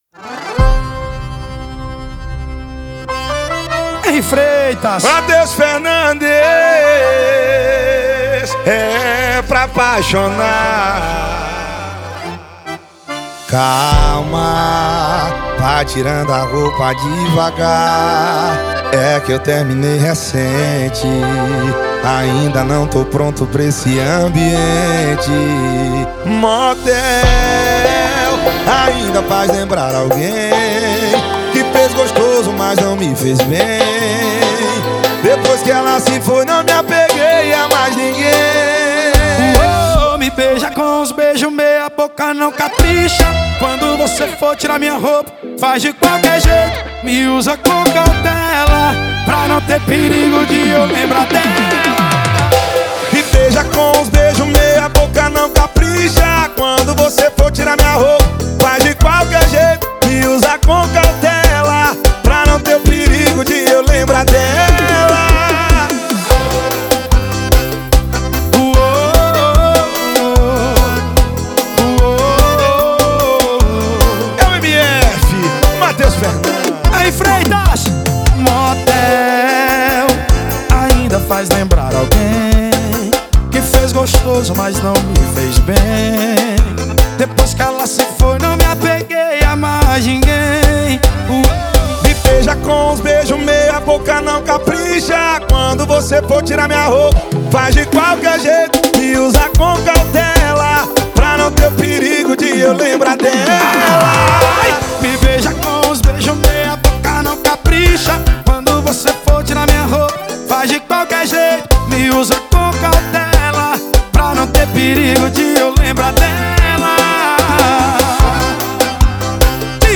2024-02-14 18:21:07 Gênero: Forró Views